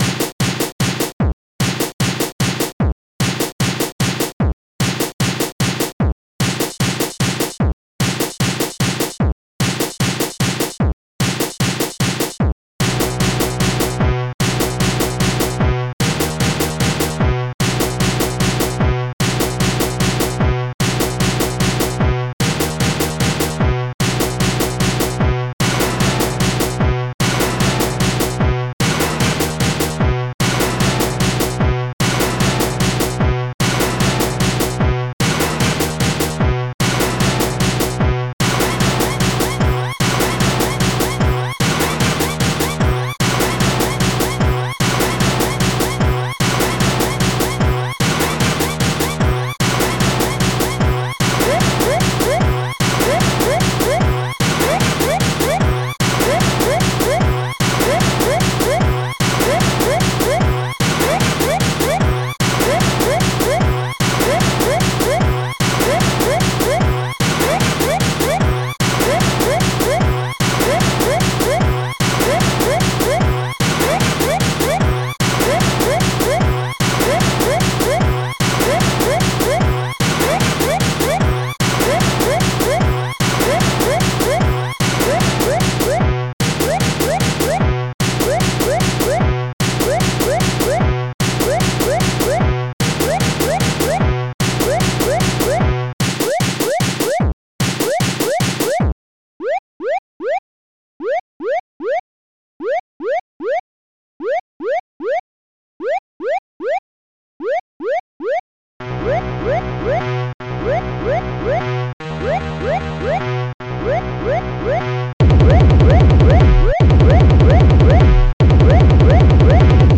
s3m (Scream Tracker 3)